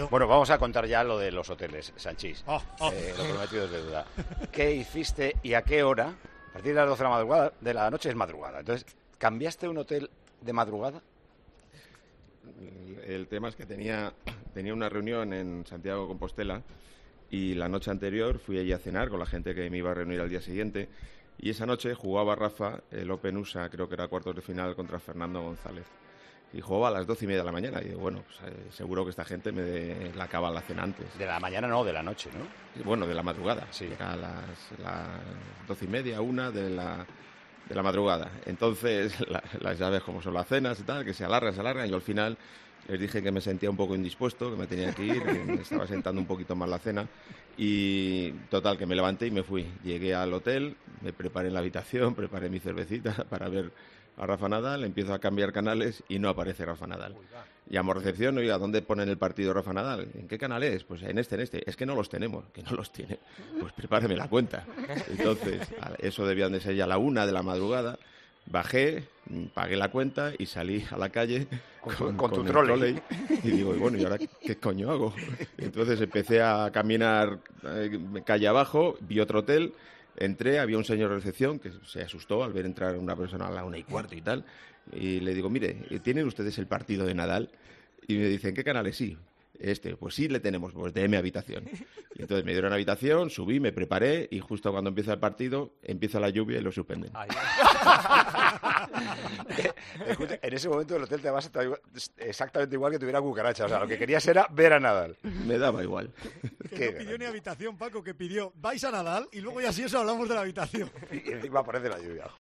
El excapitán del Real Madrid y comentarista en el programa Tiempo de Juego contó cómo se fue de una cena para ver un partido del tenista español y las aventuras que pasó